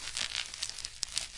电击枪射击
描述：电击枪射击的录音
Tag: 击晕 电力 射击 魔法 ZAP 电击枪